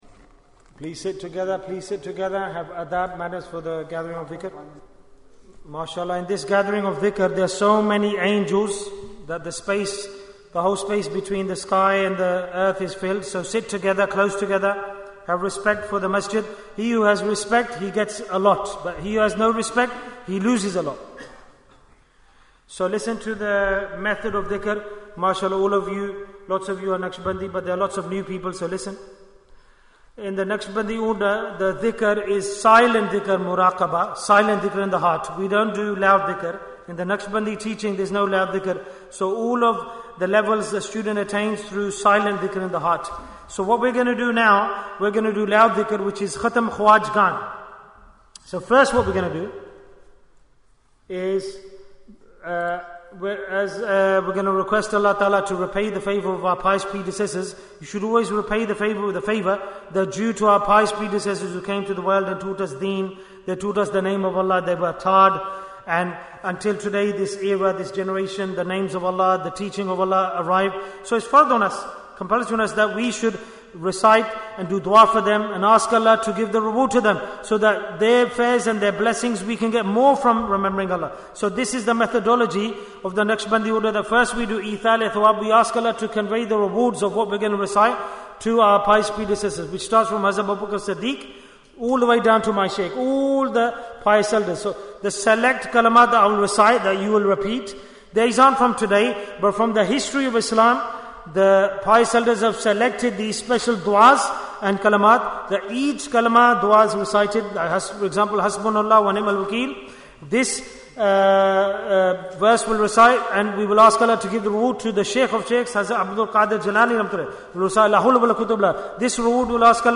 Annual Ijtema Dhikr Majlis 2024 Bayan, 63 minutes25th December, 2024